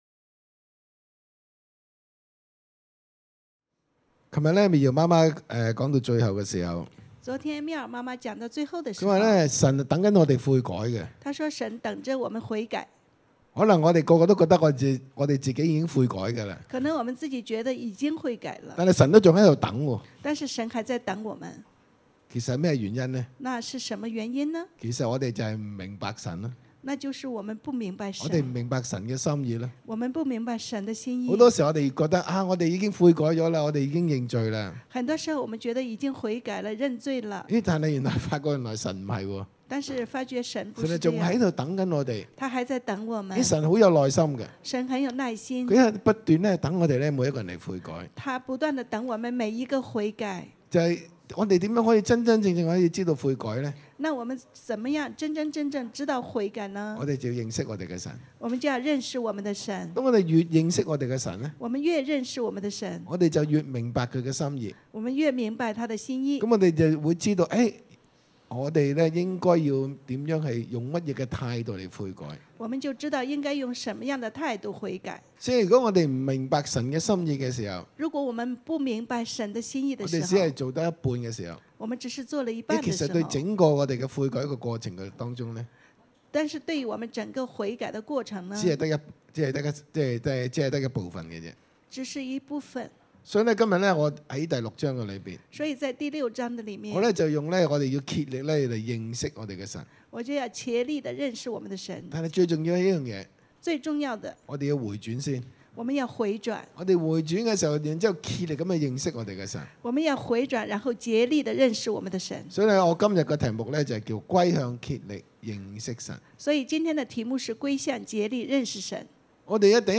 2) 分享經歷神拯救 按v1-2經文，弟兄姊妹分享被神醫治、纏裹的經歷，生命怎樣再次甦醒和興起。